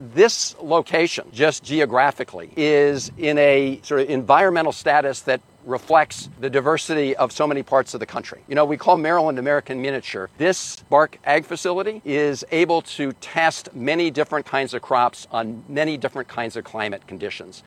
Senator Chris Van Hollen said the research done in Beltsville cannot be duplicated elsewhere…